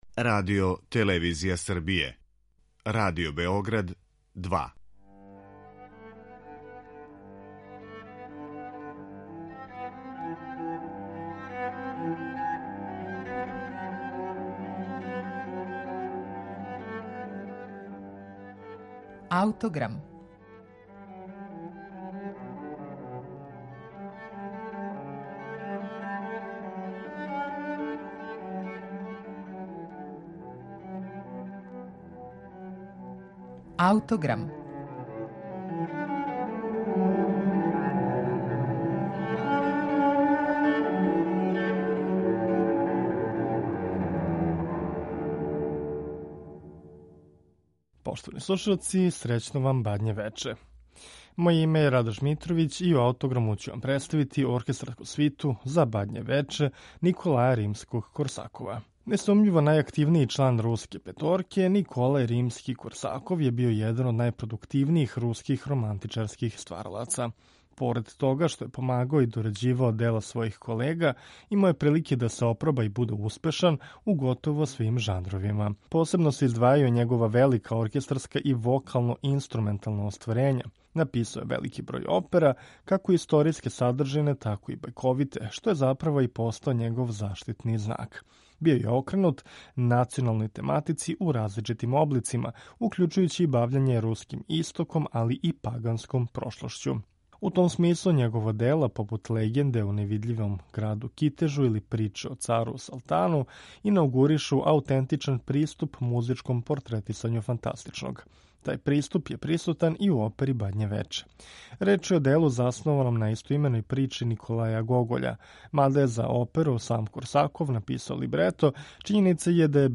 Необична, фантастична прича, музички је испраћена богатом оркестрацијом, необичним хармонским решењима и мелодијама фоклорног призвука, а што такође може да се чује и у оркестарској свити из 1904. године. Ово дело Римског Корсакова слушаћемо у извођењу Шкотског националног оркестра и Немеа Јервија.